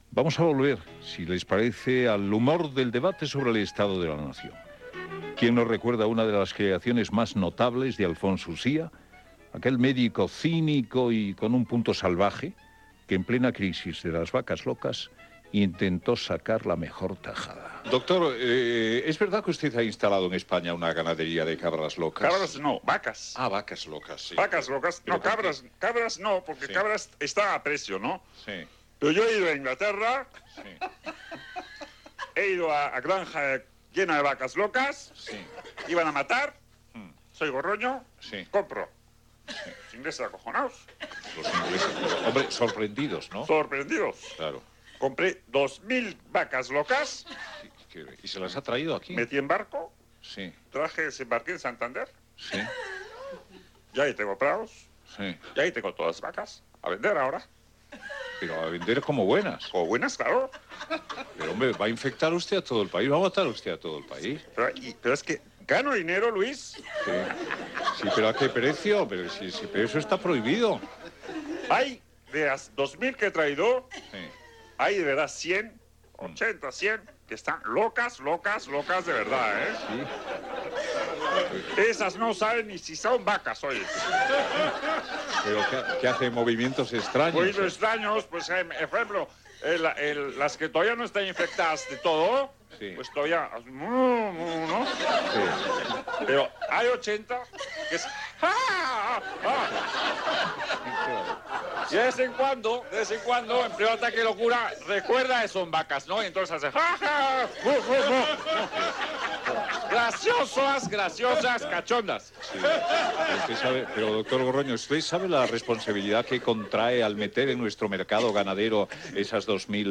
Info-entreteniment
Doctor Gorroño (Alfonso Usía)
Padre Escolano (Alfonso Usía)